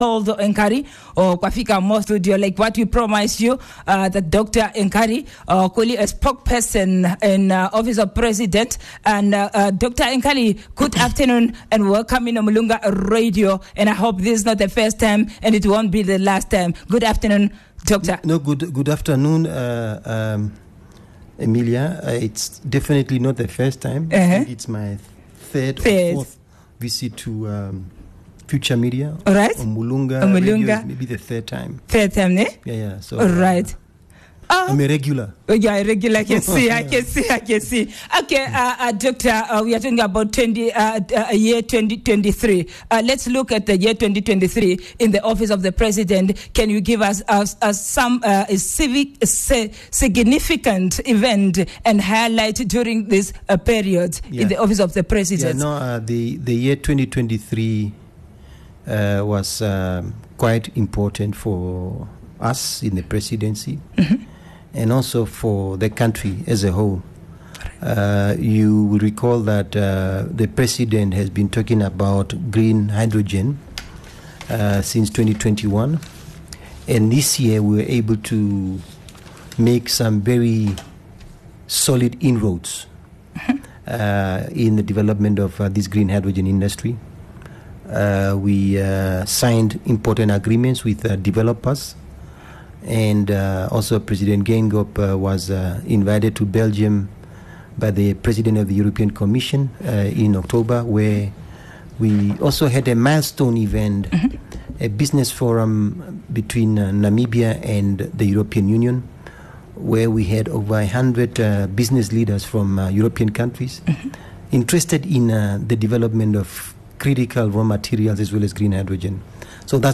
18 Dec The interview